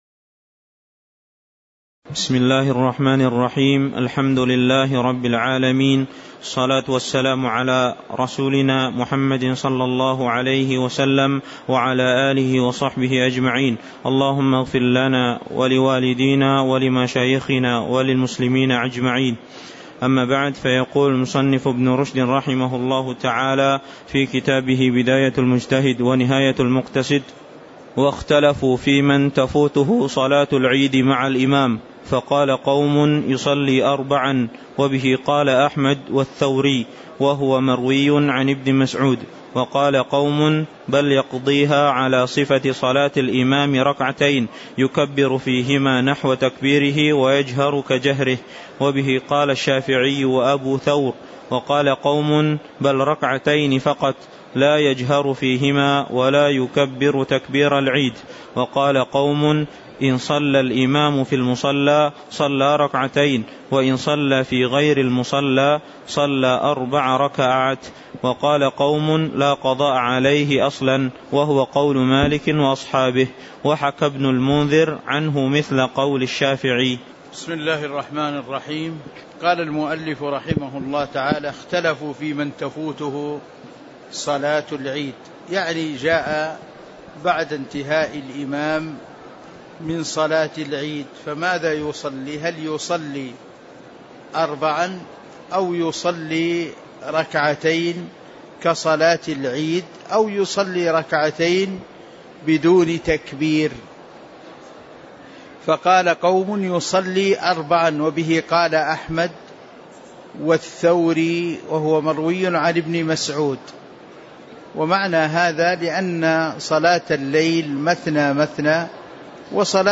تاريخ النشر ٢١ رجب ١٤٤٤ هـ المكان: المسجد النبوي الشيخ